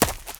High Quality Footsteps
STEPS Leaves, Run 30.wav